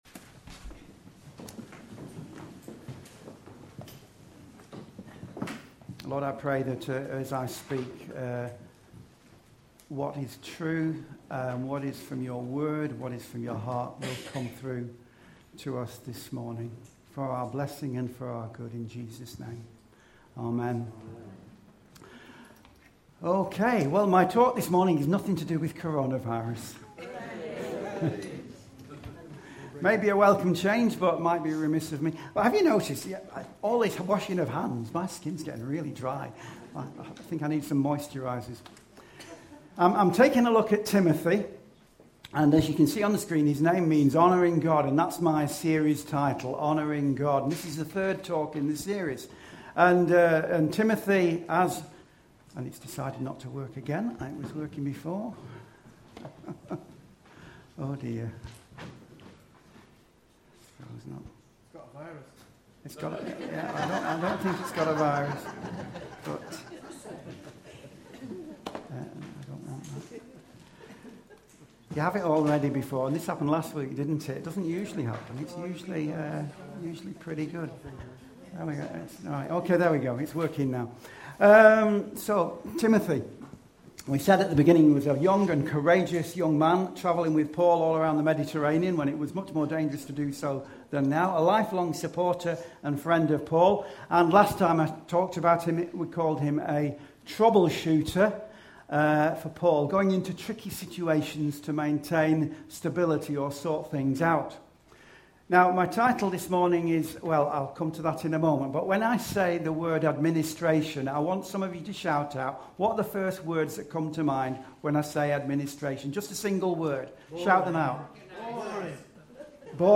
The series began before covid-19 hit and the first three talks were recorded in audio with slides.